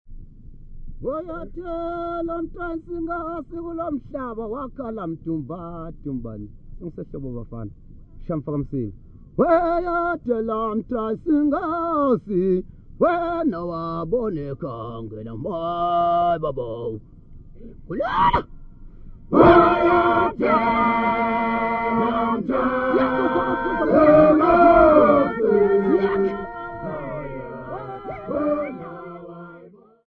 Folk music--Africa
Field recordings
Africa Eswatini Usutu Forest f-sq
sound recording-musical
Unaccompanied Mbube song.